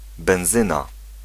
Ääntäminen
US : IPA : [ˈɡæs.ə.ˌlin]